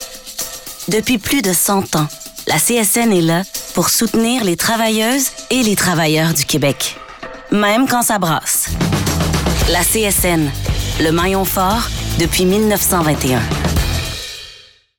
Voix de annonceur – CSN
authentique, inspirante